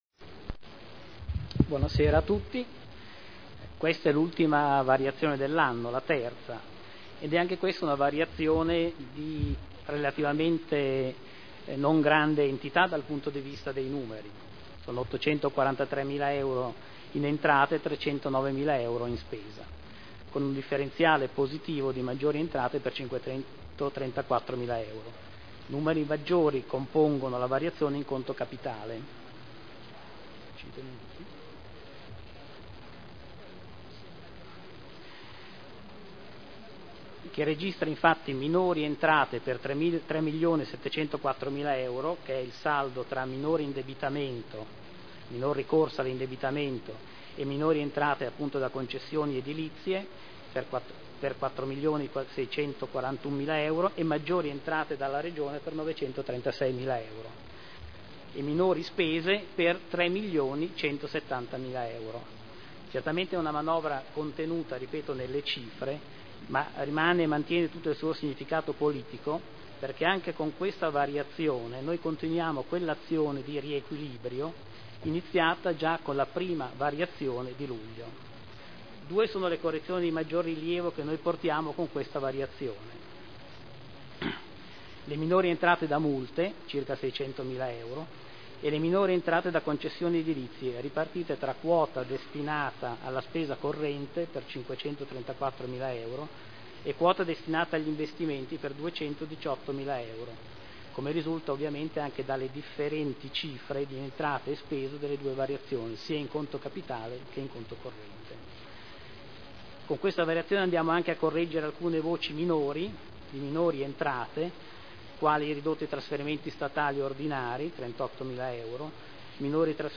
Alvaro Colombo — Sito Audio Consiglio Comunale